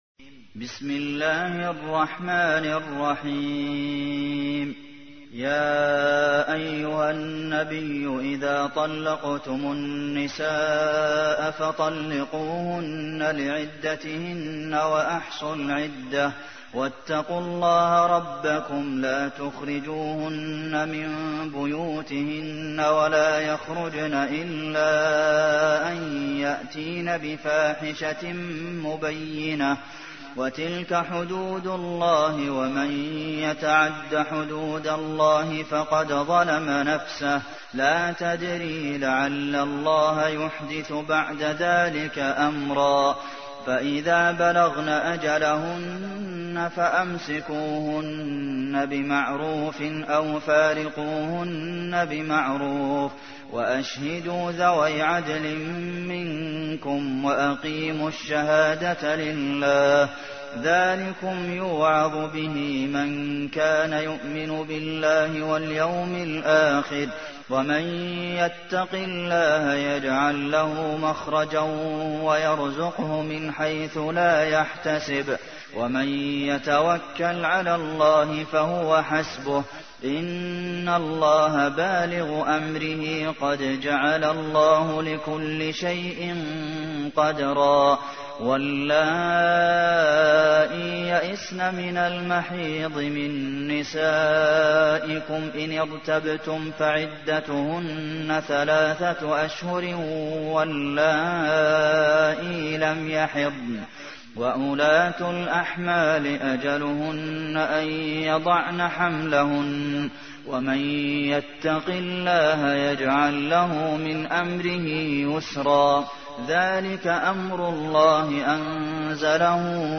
تحميل : 65. سورة الطلاق / القارئ عبد المحسن قاسم / القرآن الكريم / موقع يا حسين